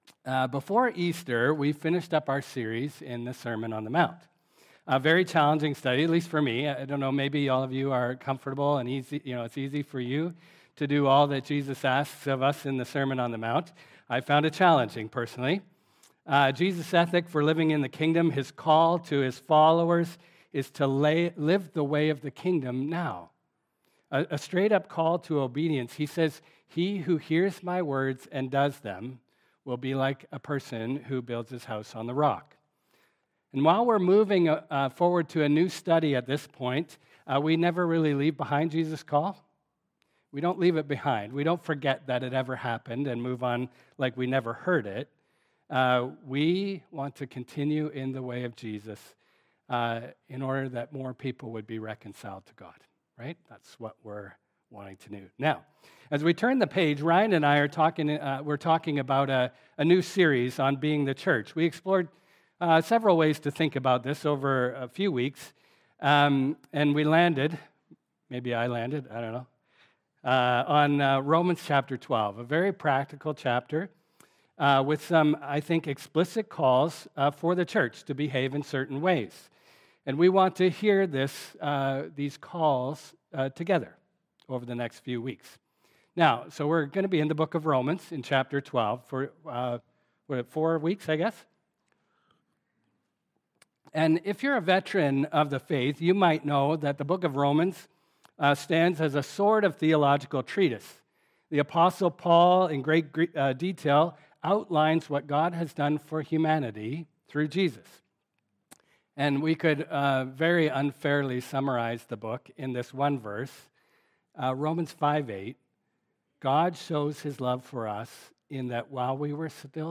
Sermons | Bethany Baptist Church